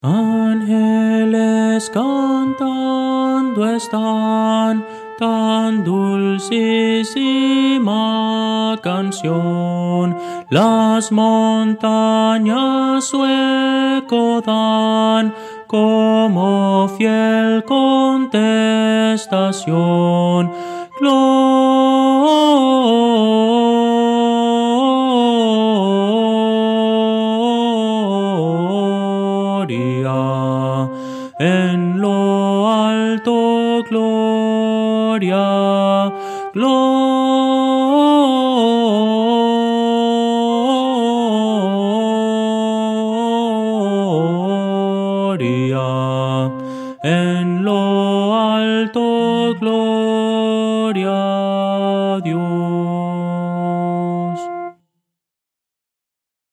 Voces para coro
Soprano –